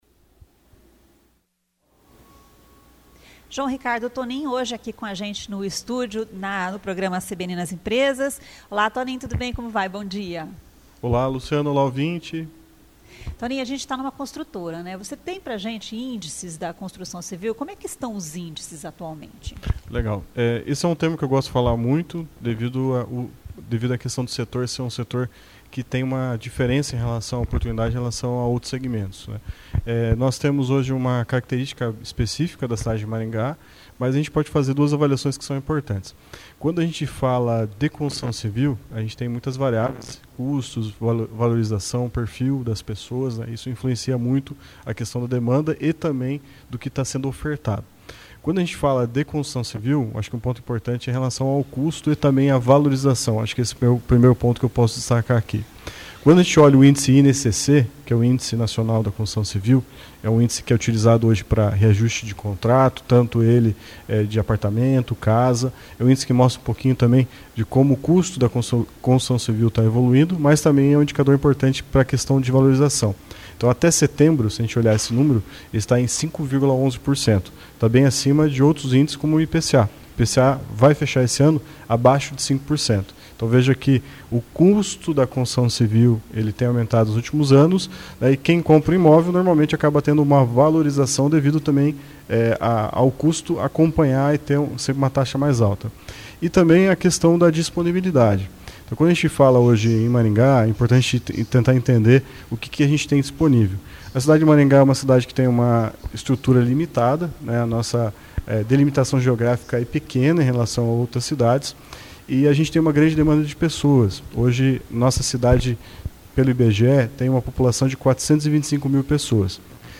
Esta edição da coluna 'Economia em Foco' foi transmitida ao vivo diretamente da sede da construtora Trifold, dentro do projeto CBN nas Empresas.